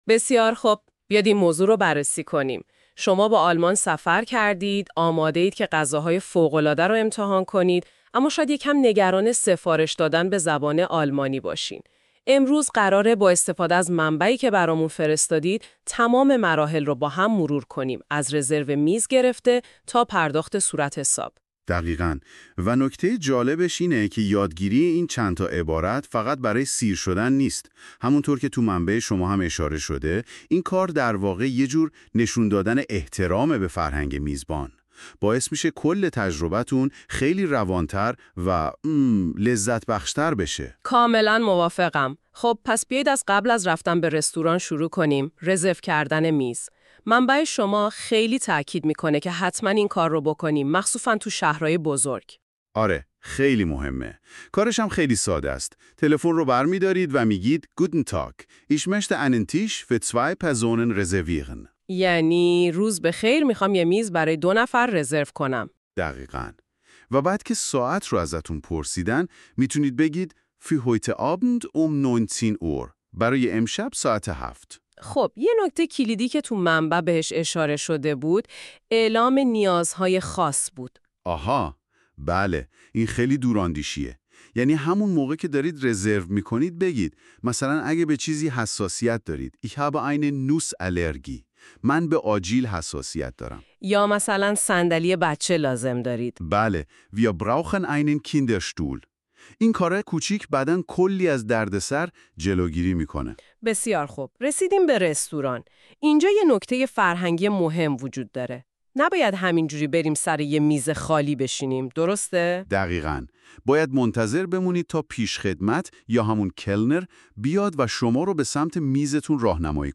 german-conversation-in-a-restaurant.mp3